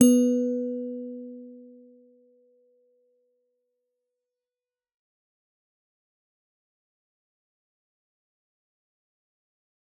G_Musicbox-B3-f.wav